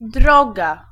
Ääntäminen
Ääntäminen Tuntematon aksentti: IPA: [ˈd̪rɔɡa] IPA: /ˈdrɔ.ɡa/ Haettu sana löytyi näillä lähdekielillä: puola Käännös Konteksti Ääninäyte Substantiivit 1. way US UK 2. road UK US 3. distance arkikielessä US Suku: f .